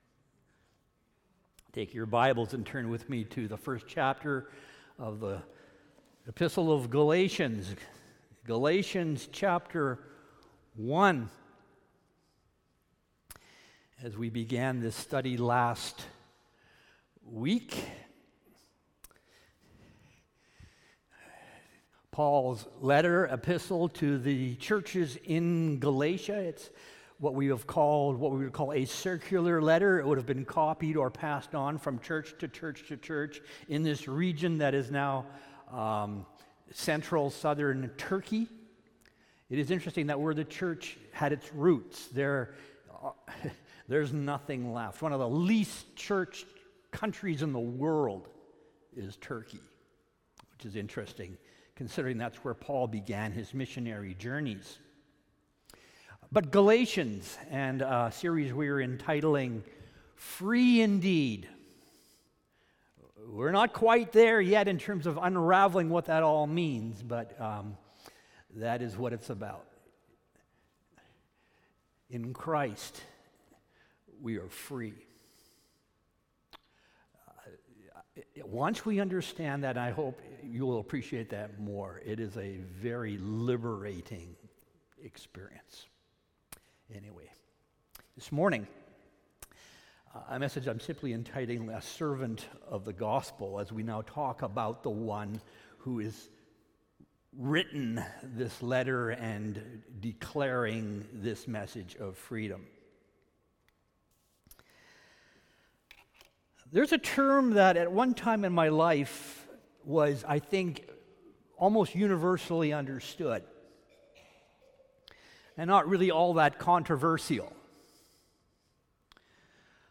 Sermons | Richmond Alliance Church